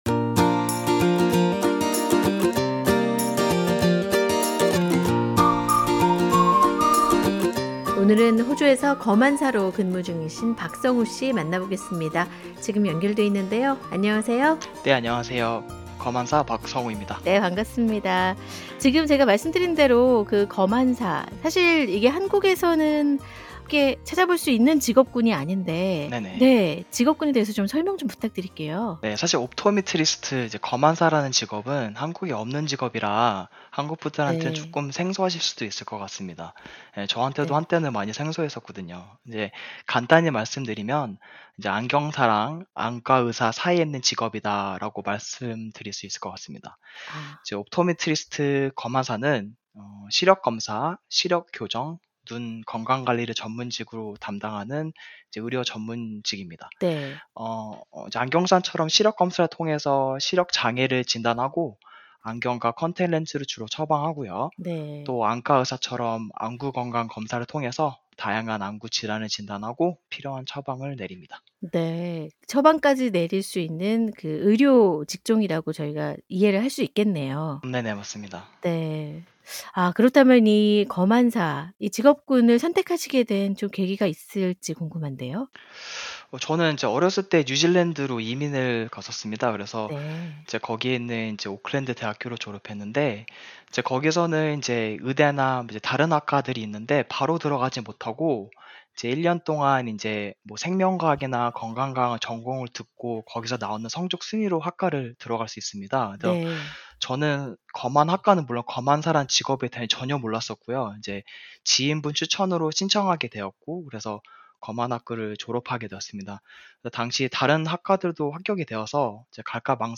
JOB 인터뷰